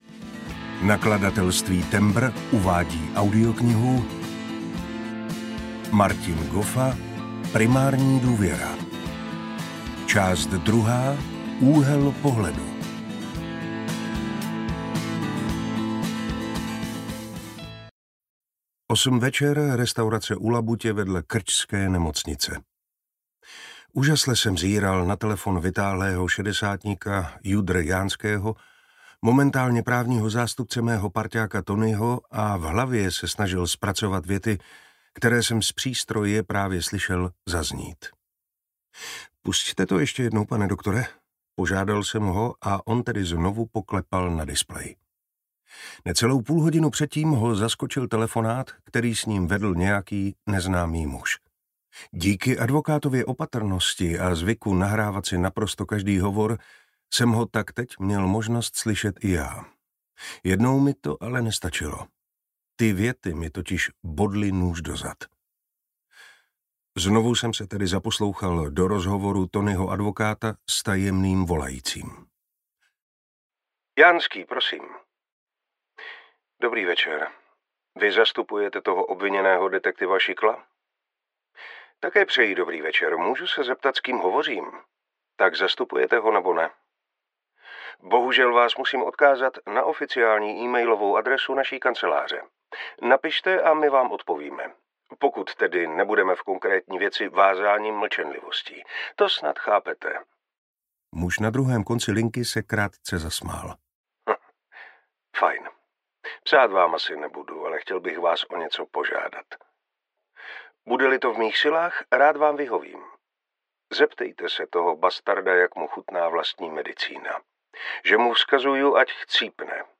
Ukázka z knihy
primarni-duvera-ii-uhel-pohledu-audiokniha